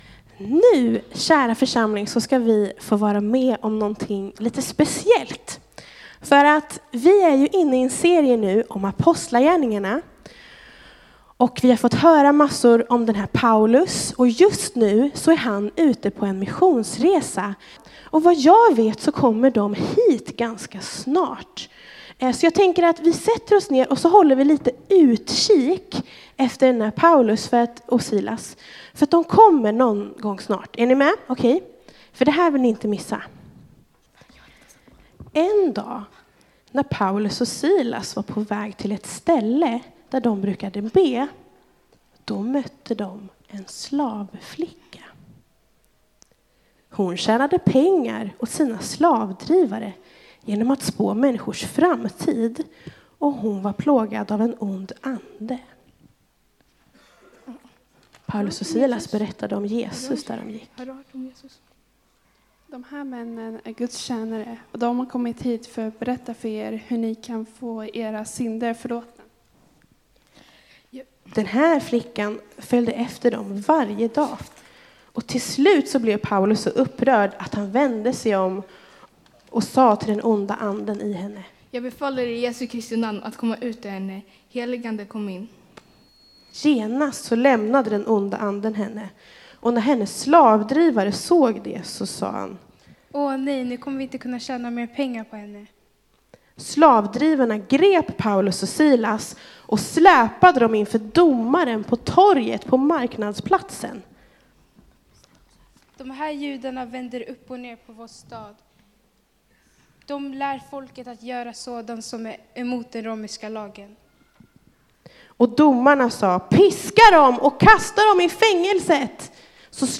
drama & predikan